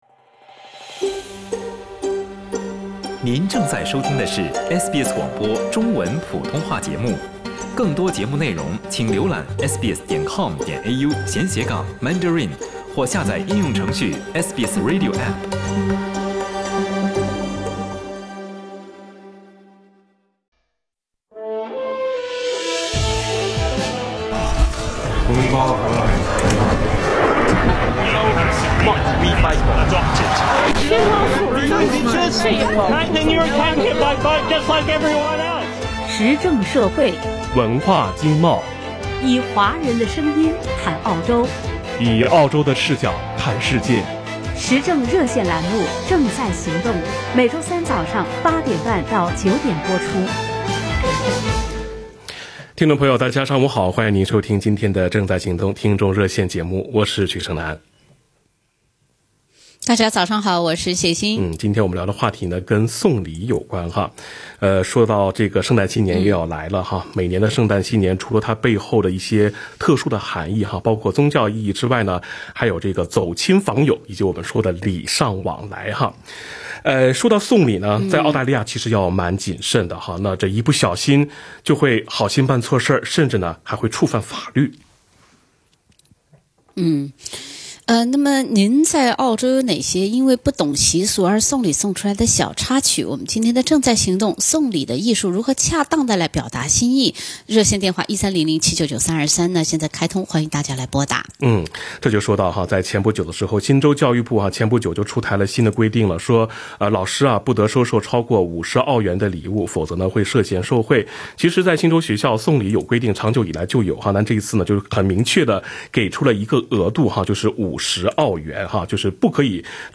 (热线听众发言总结不代表本台观点）